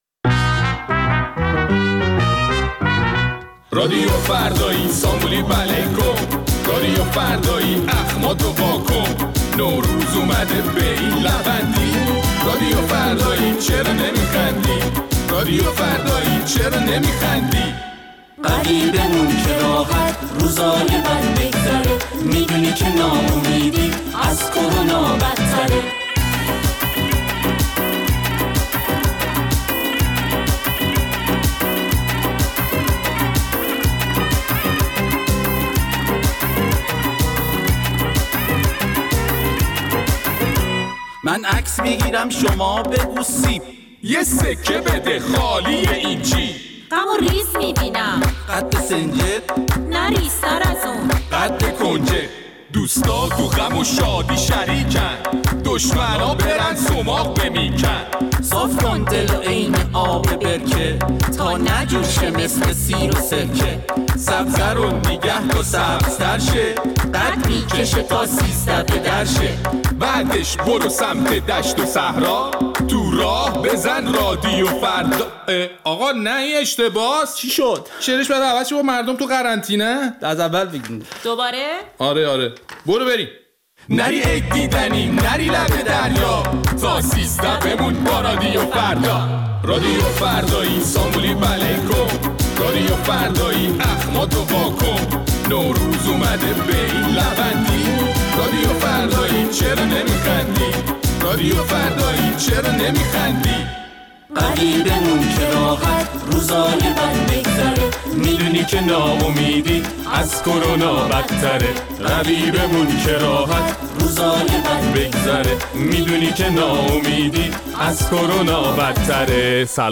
در این برنامه ادامه نظرات شنوندگان ایستگاه فردا را در مورد سفرهای نوروزی تعدادی از ایرانی‌ها با وجود همه هشدارها می‌شنویم.